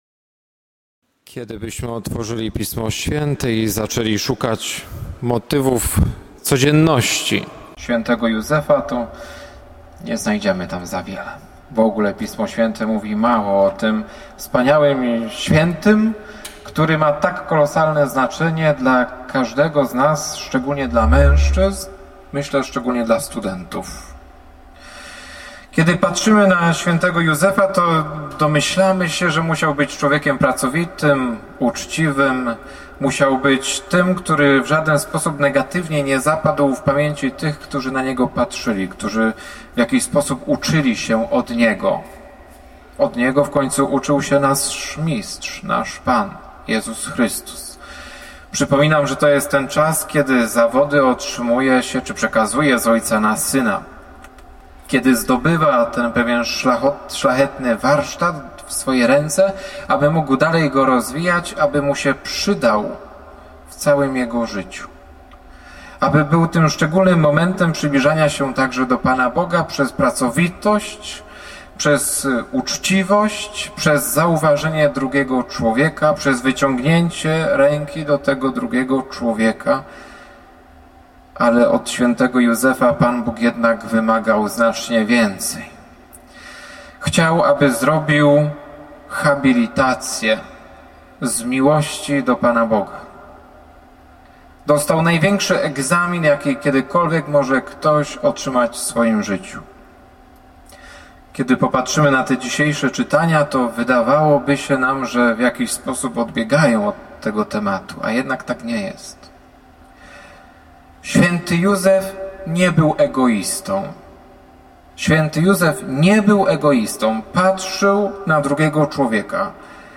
Zajrzyj do ksi��ki: Pos�uchaj: I dzie� nowenny - homilia ks. bpa Grzegorza Balcerka Pos�uchaj: II dzie� nowenny - homilia ks.